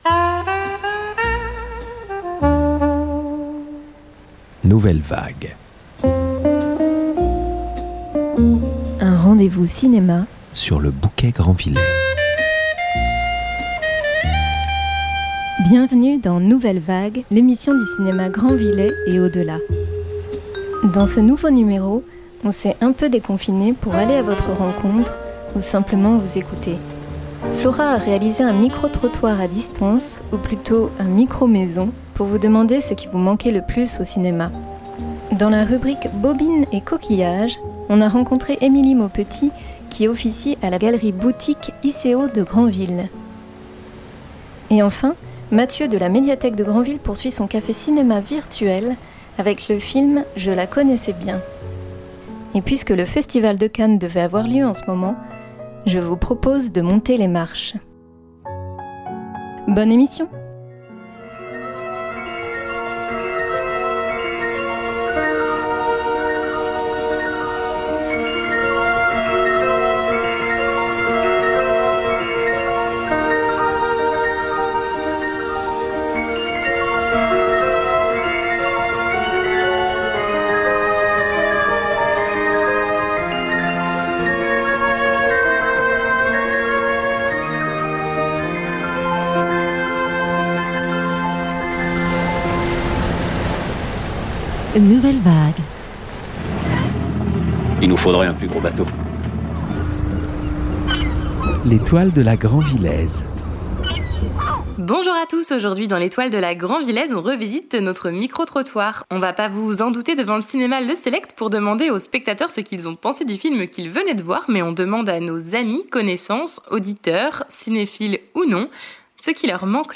Les extraits de musiques de films qui ponctuent l’émission
Animatrices radio